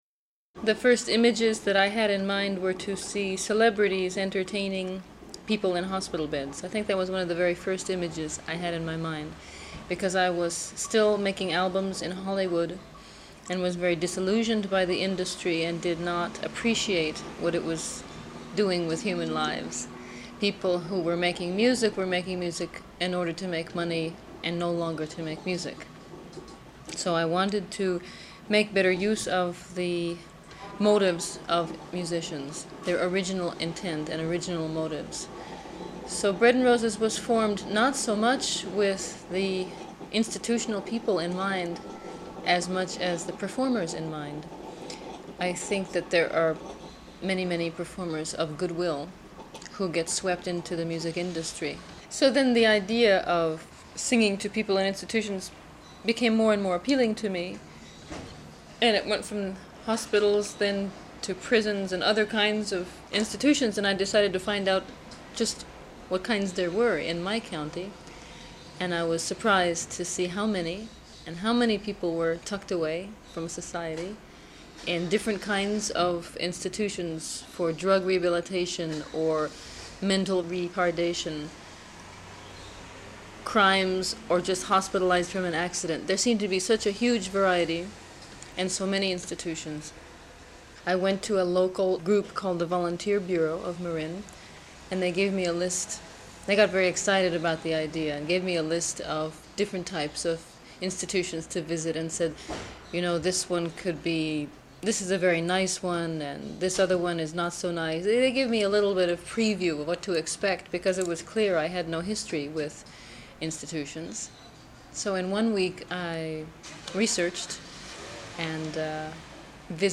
INTERVIEWS WITH MIMI:
for German public radio